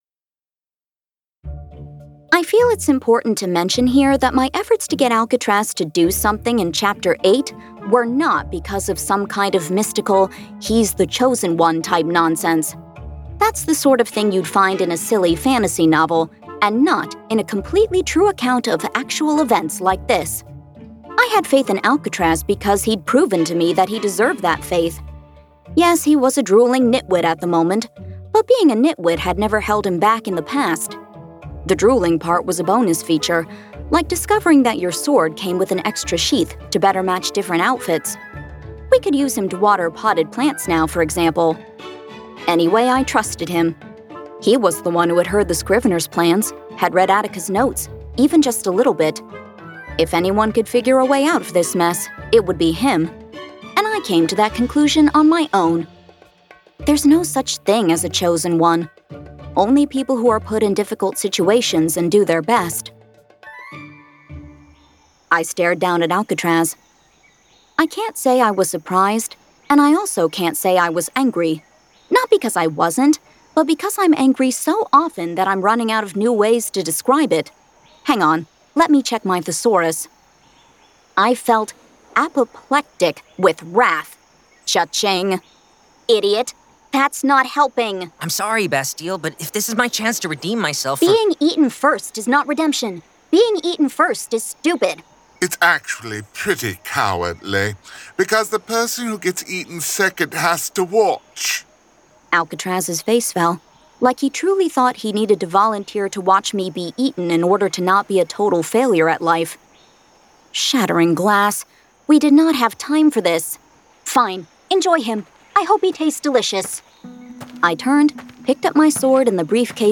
This is an actively updated collection of graphic audio material.